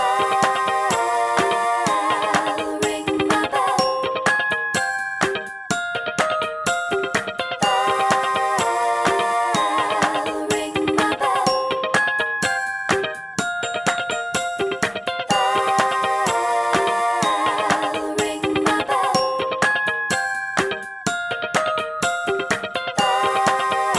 no kit bass or main guitar Disco 3:42 Buy £1.50